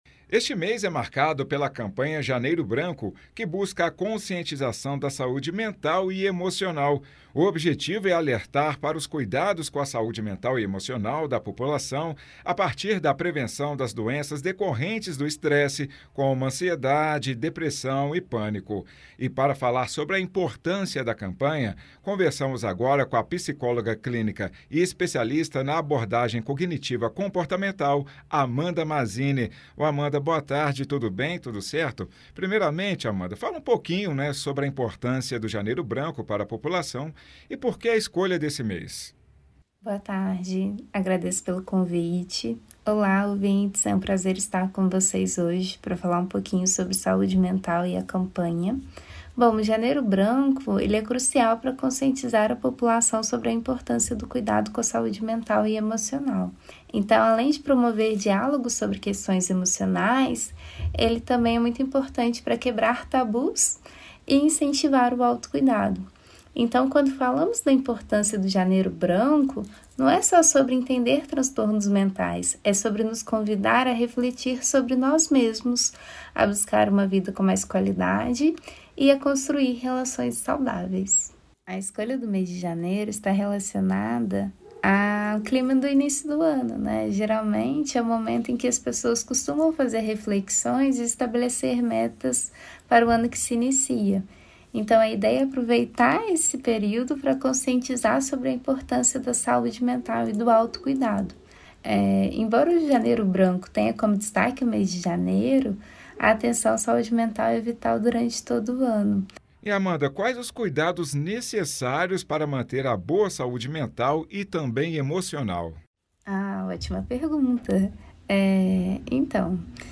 08.01_Itatiaia-Entrevista-Janeiro-Branco.mp3